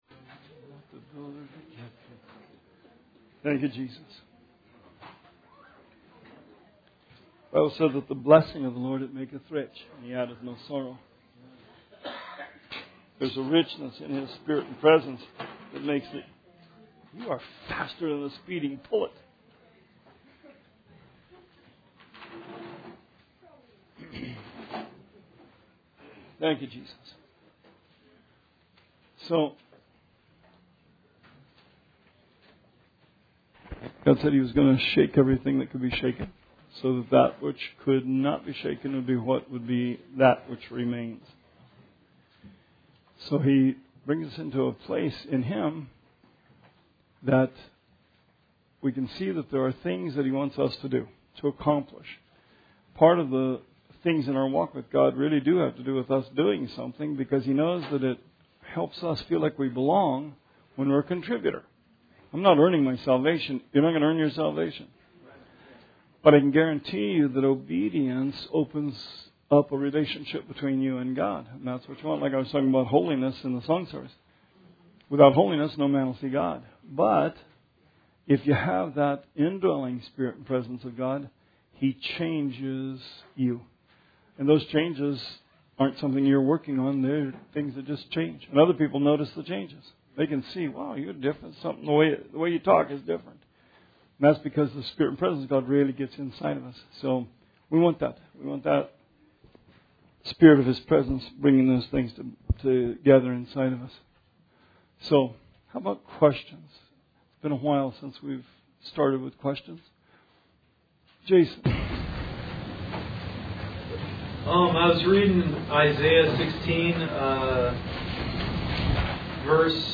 Bible Study 6/14/17